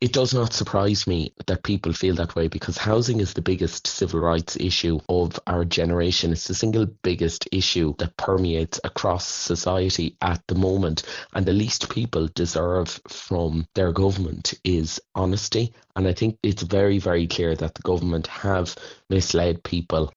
Labour’s Housing Spokesperson, Conor Sheehan, says single people are being left behind by our housing strategies: